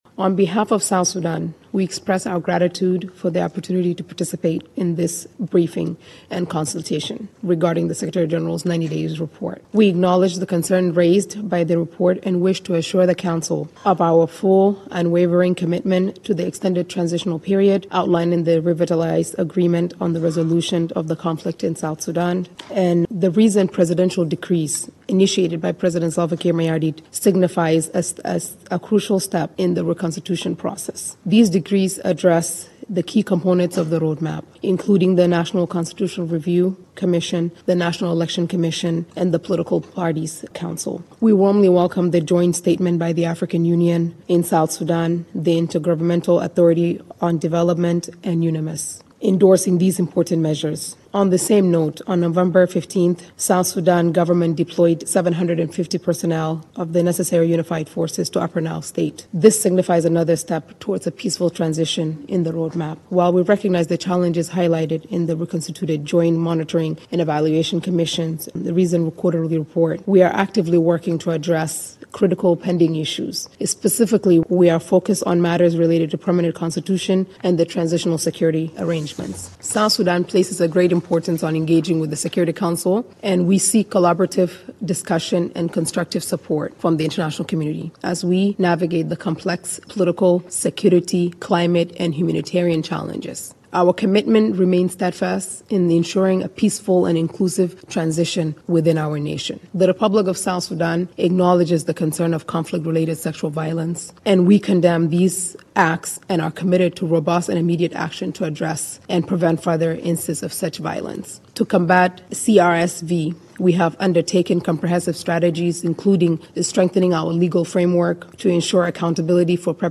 Statement of South Sudan Deputy Permanent Representative at the Security Council